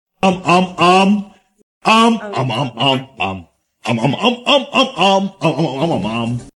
голосовые мемы